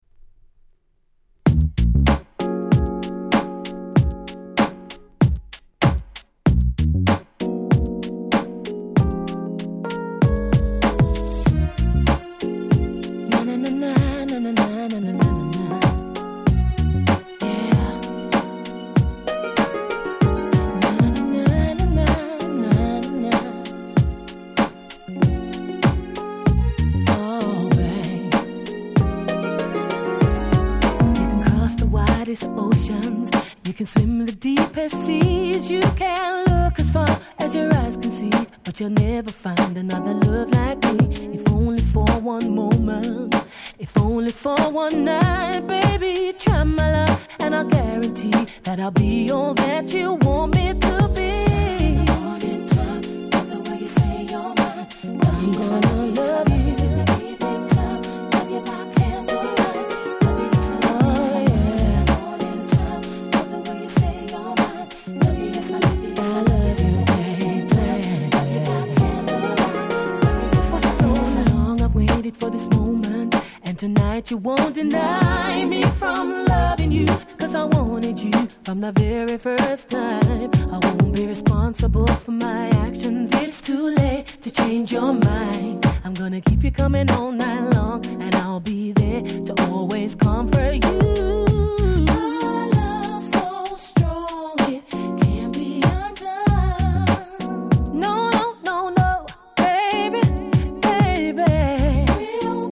02' Nice Cover R&B !!